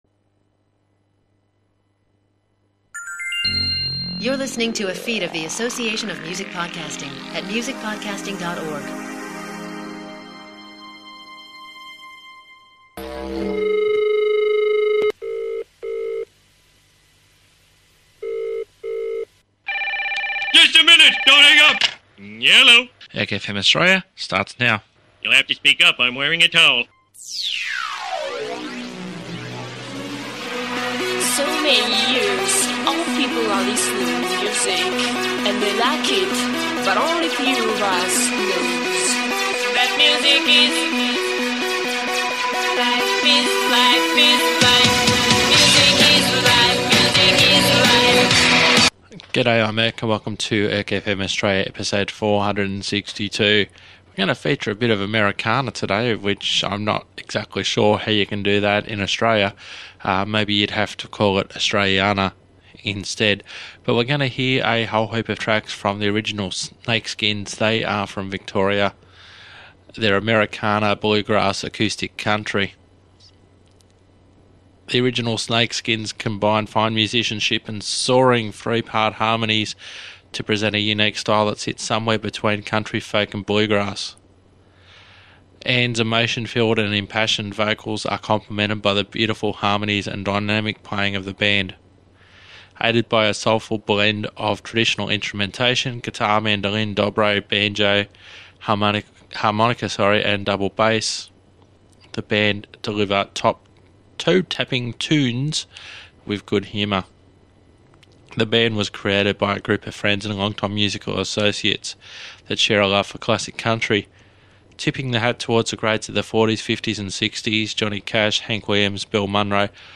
Americana